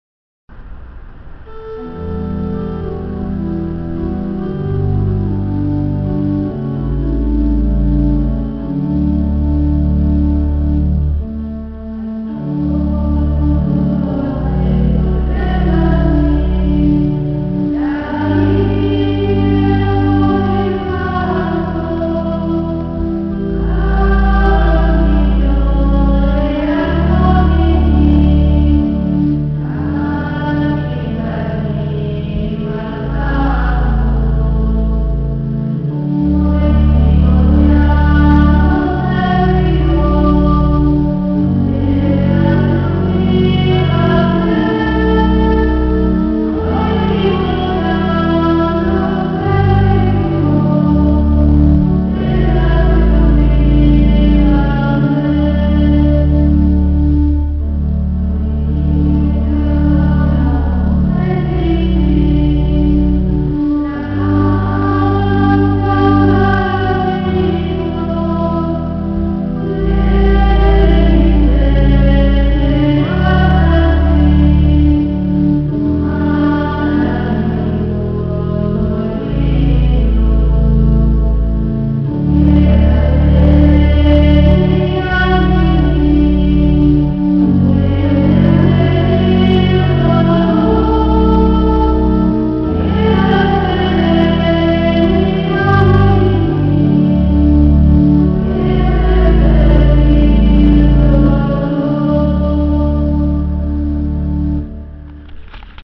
L’intero fascicolo è scaricabile in formato pdf; i testi dei singoli canti in formato *.txt. Anche le registrazioni in mp3 hanno valore di demo, cioè un aiuto ad imparare il canto. Le registrazioni sono state fatte qualche anno fa dalle Monache Redentoriste di Scala (purtroppo, non di tutti i canti).